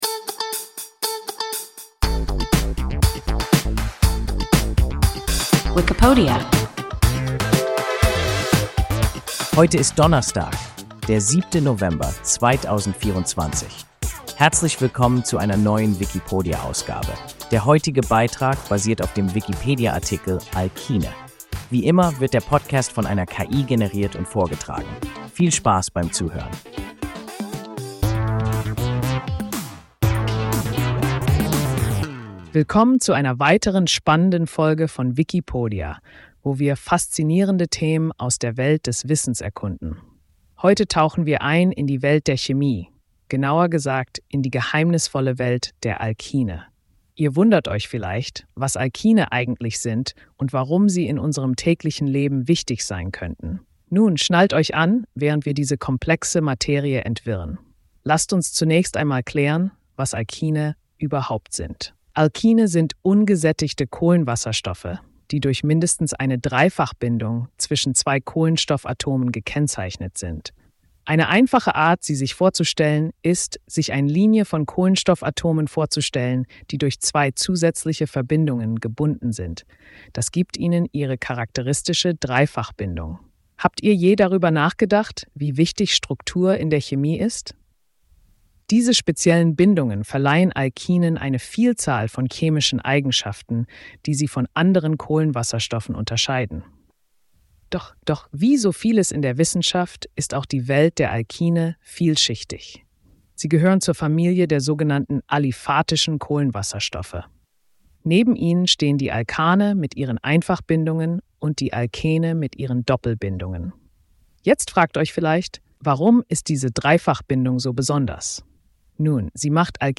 Alkine – WIKIPODIA – ein KI Podcast